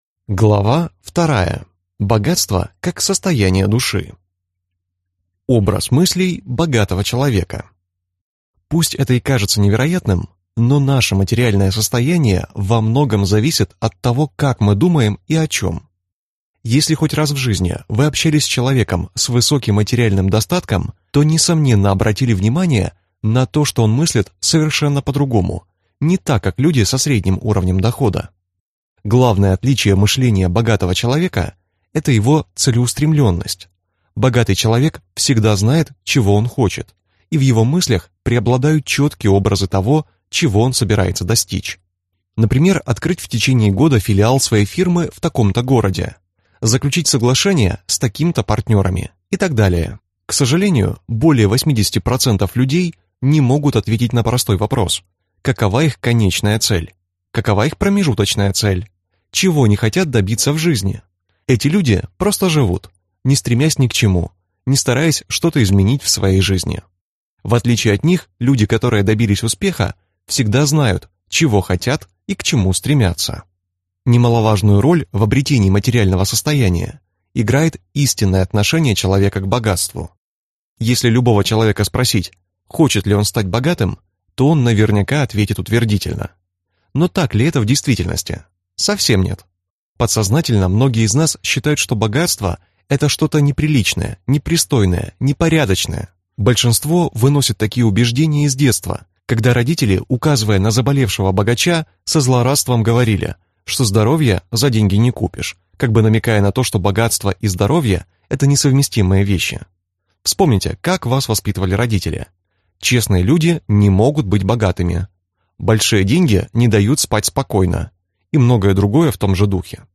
Аудиокнига Как стать богатым | Библиотека аудиокниг